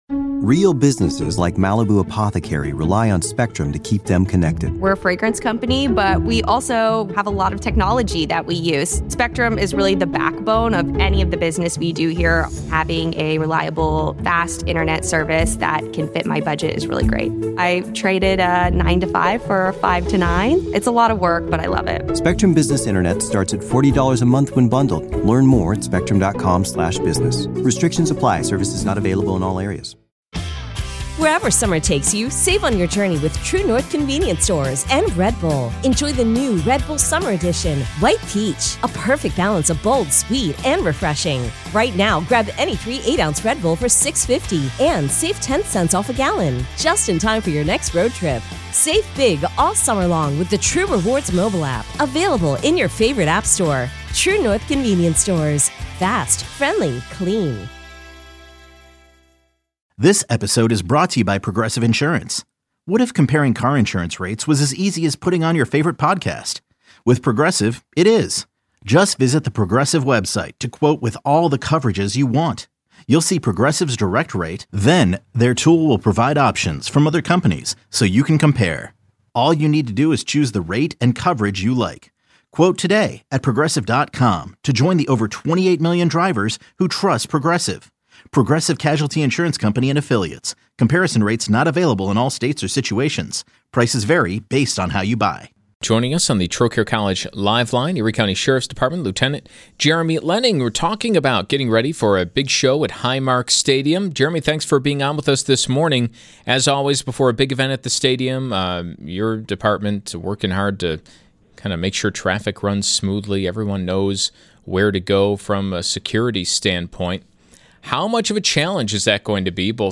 Collection of LIVE interviews from Buffalo's Early News on WBEN